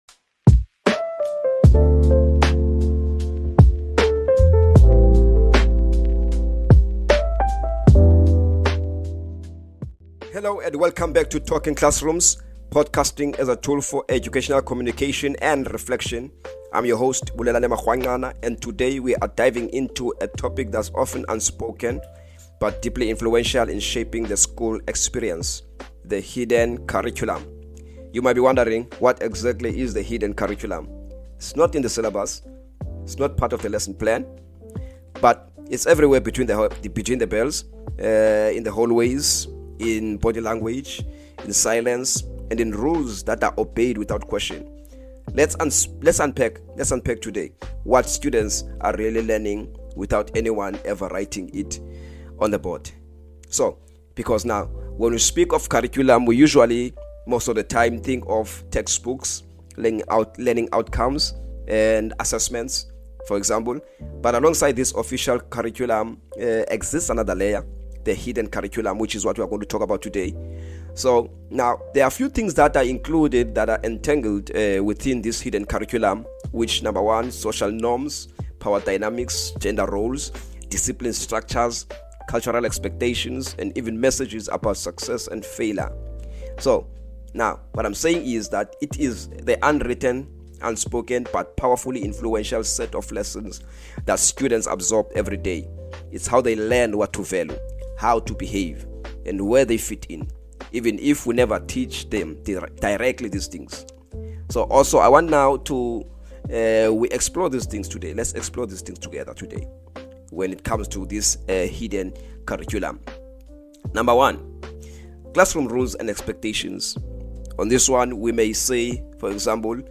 In this thought-provoking solo episode